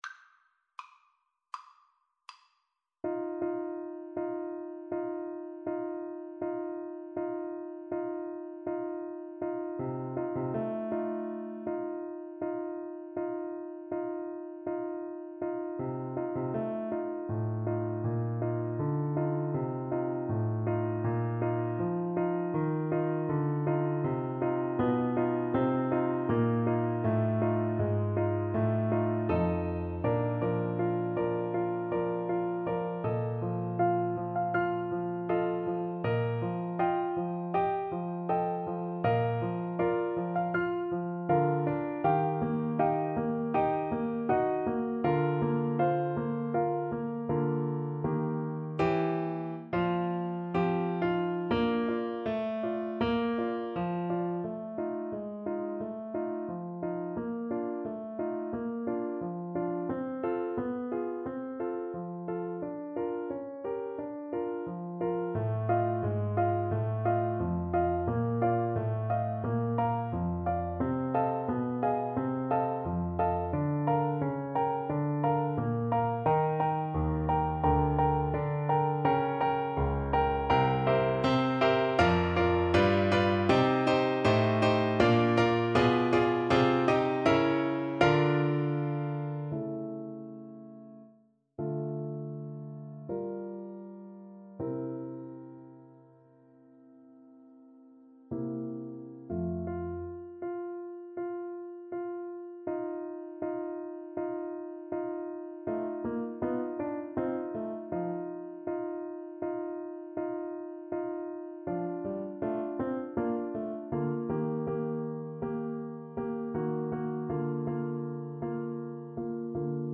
Andante espressivo
Classical (View more Classical French Horn Music)